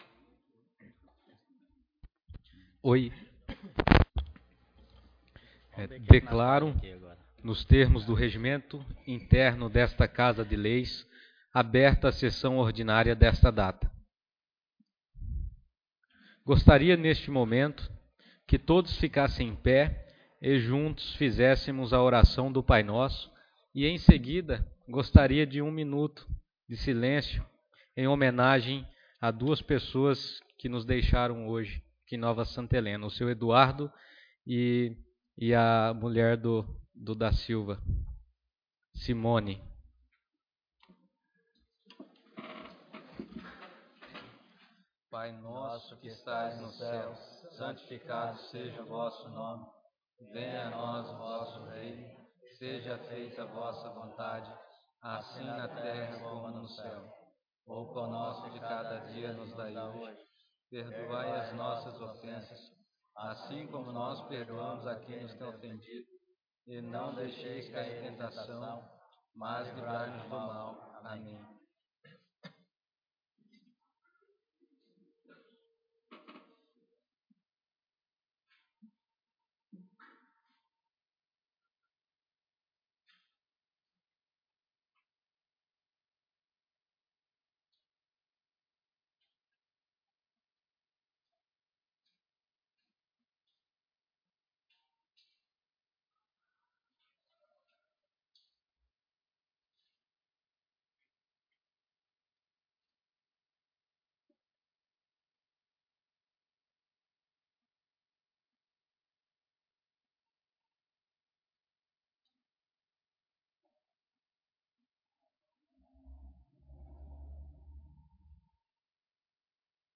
Sessão Ordinária 13/02/2017